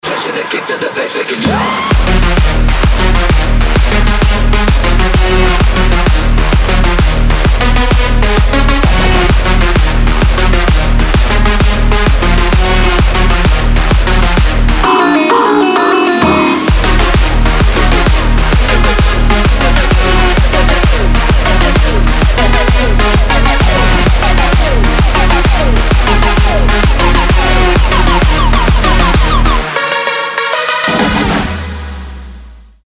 • Качество: 192, Stereo
громкие
заводные
dance
без слов
club
качающие
electro
задорные
бас
Крутой танцевальный рингтон без слов